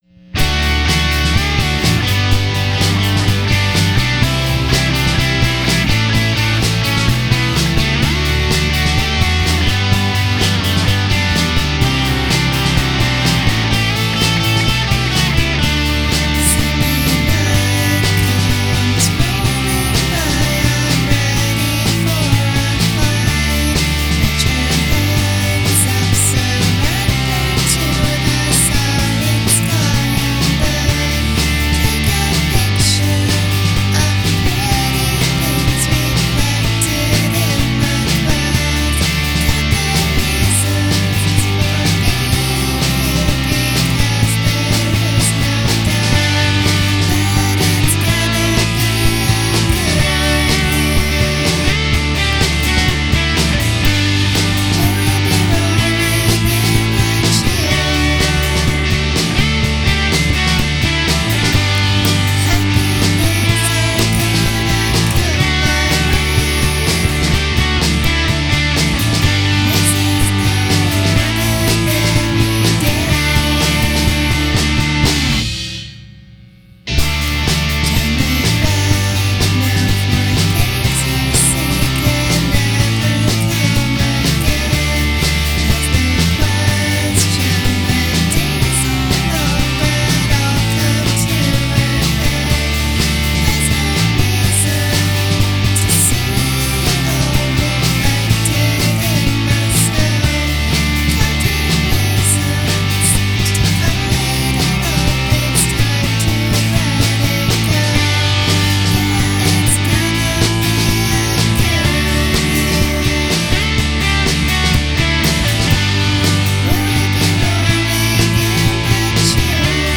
puro indie rock Anni Novanta
ed è una raccolta adorabile di pop veloce a bassa fedeltà